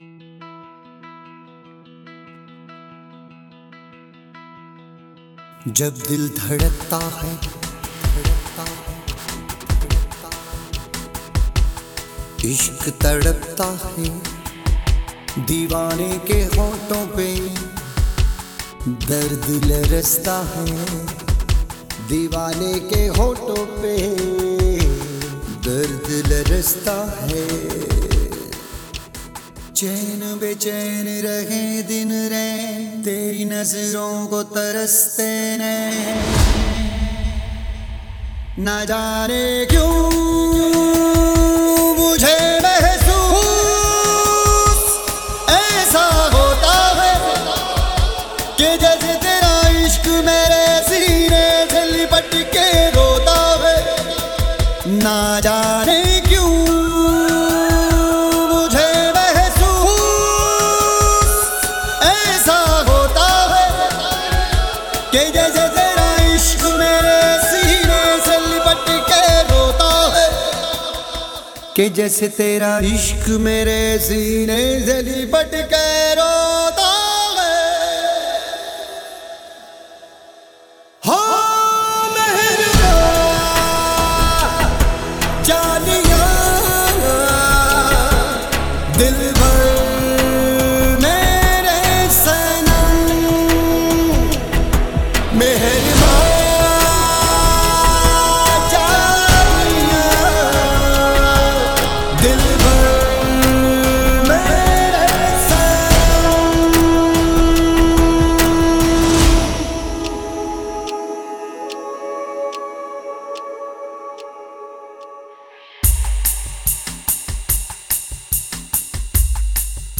IndiPop